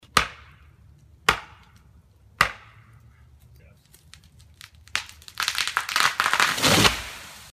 Звуки дерева